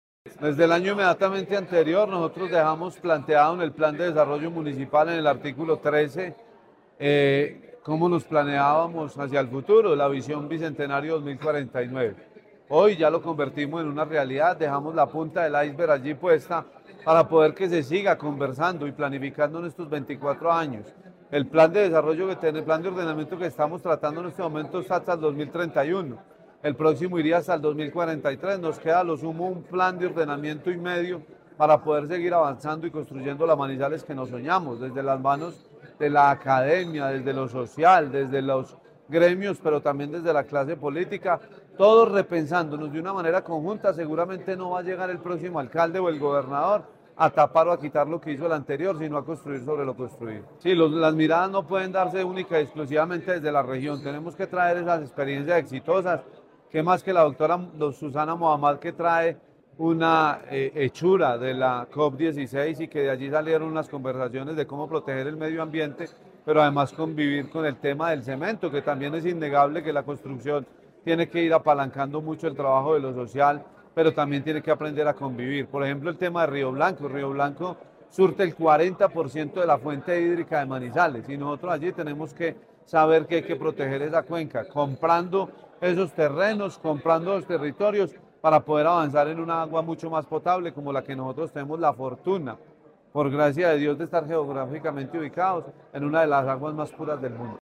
Con el objetivo de promover una visión estratégica y prospectiva de Manizales hacia el año 2049, se llevó a cabo el “Foro Bicentenario», un espacio de diálogo abierto para construir colectivamente políticas públicas, proyectos estratégicos y planes de desarrollo sostenible que consoliden a la ciudad como un referente de innovación, competitividad y bienestar en Colombia.
Julián Osorio, presidente del Concejo de Manizales